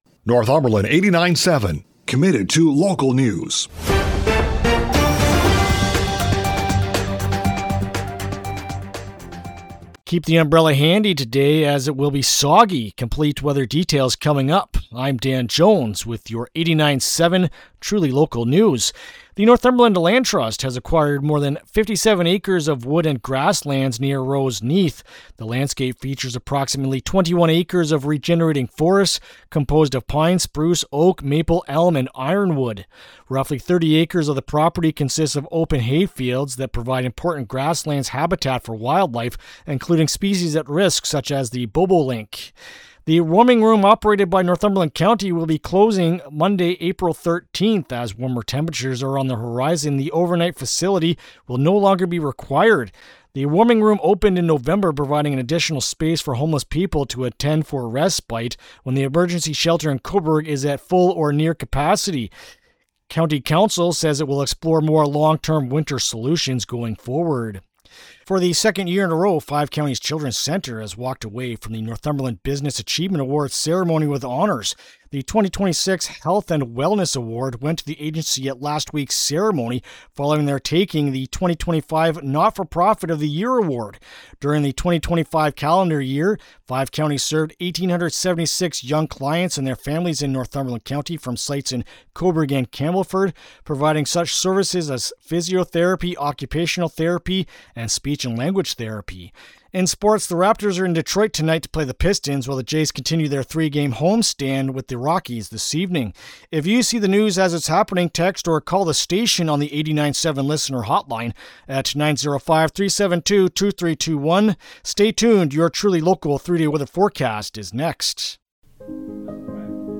Tuesday-March-31-AM-News-2.mp3